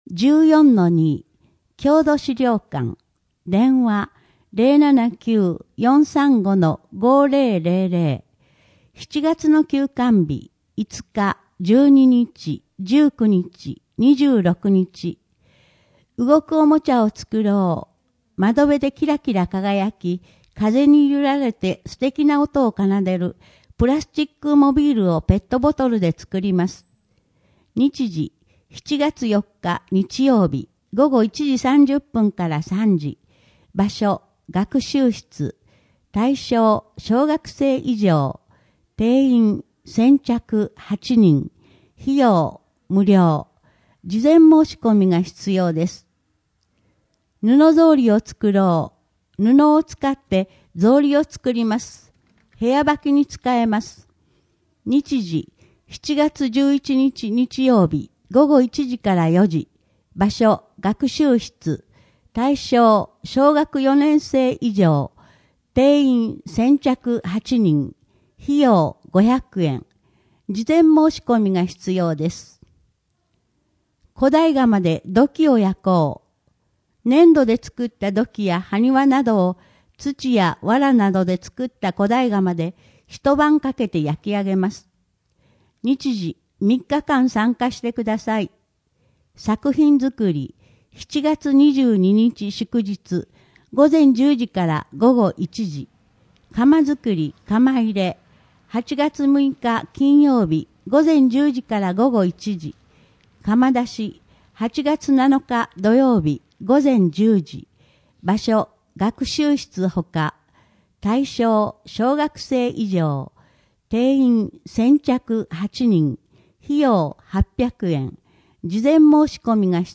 声の「広報はりま」7月号
声の「広報はりま」はボランティアグループ「のぎく」のご協力により作成されています。